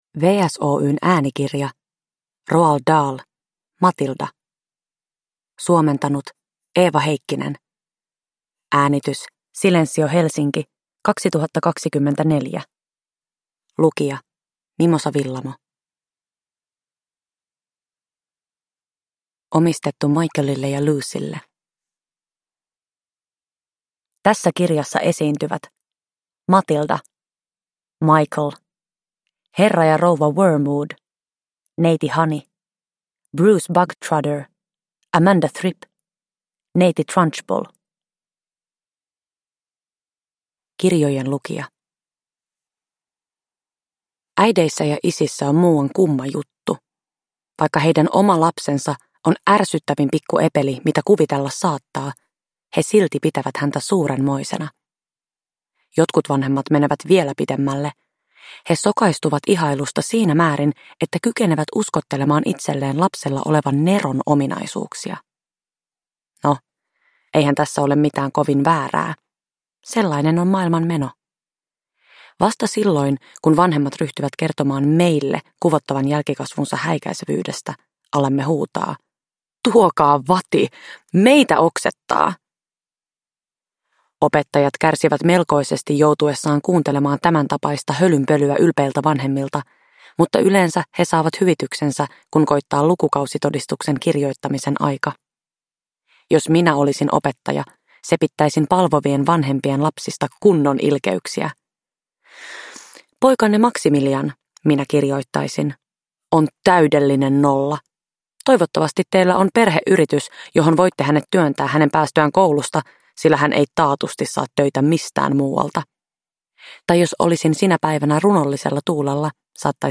Matilda – Ljudbok